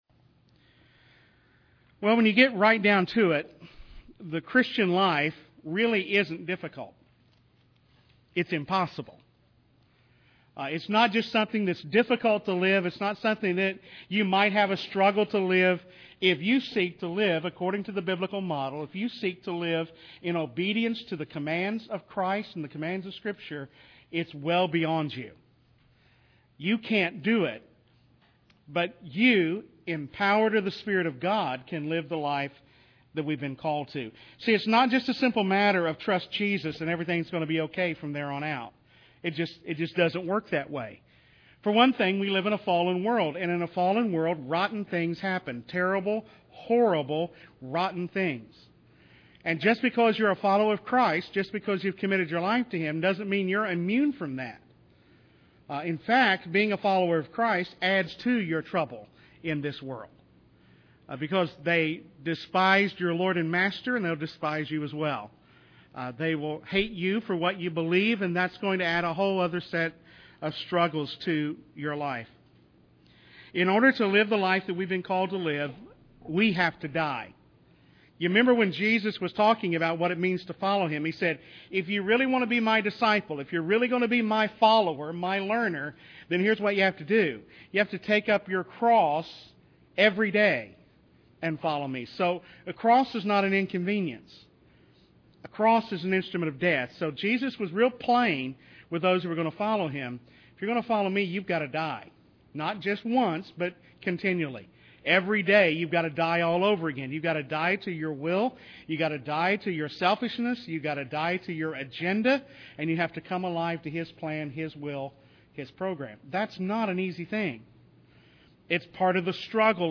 A Wednesday-evening Bible study from Genesis 50:15-21.